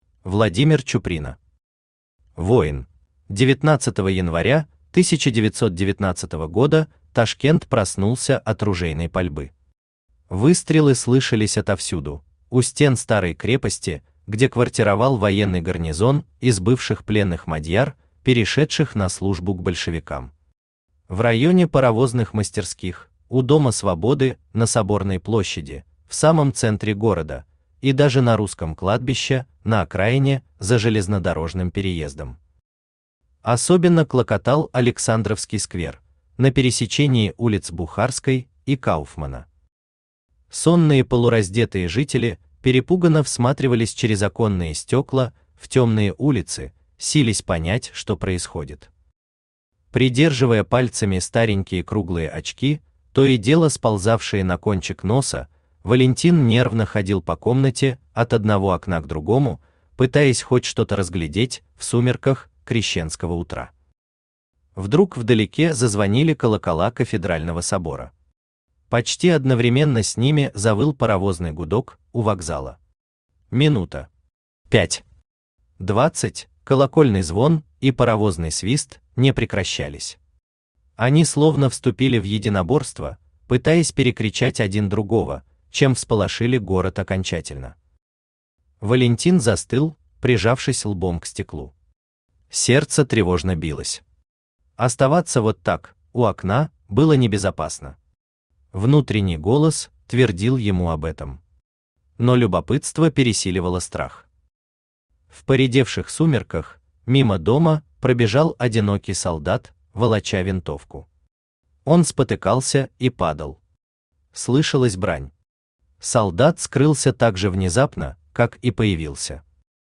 Aудиокнига Воин Автор Владимир Иванович Чуприна Читает аудиокнигу Авточтец ЛитРес.